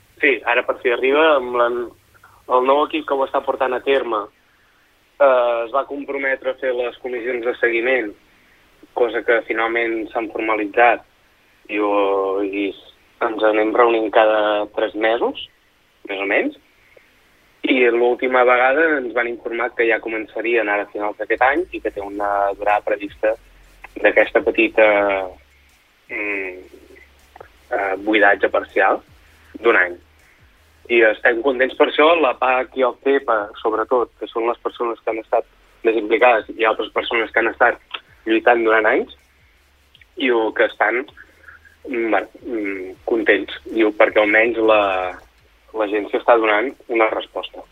Entrevistes SupermatíNotícies
Per parlar de les previsions i la valoració d’aquest buidatge de l’abocador ens visita l’alcalde de Cruïlles, Monells i Sant Sadurní de l’Heura, Daniel Encinas.